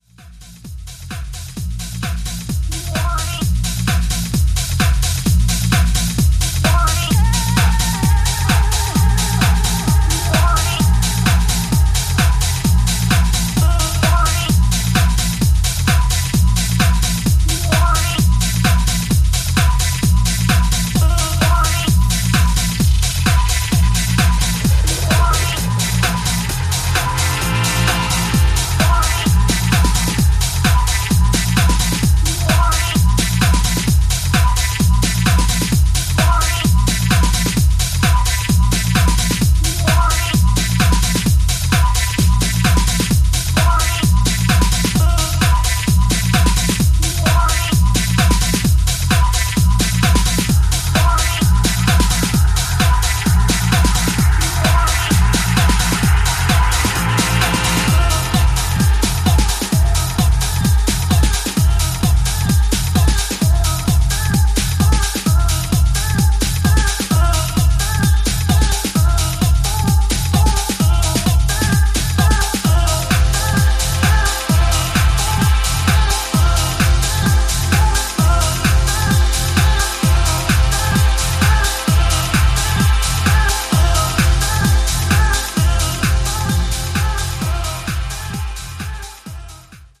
高い位置でテンションをキープし続ける秀逸な内容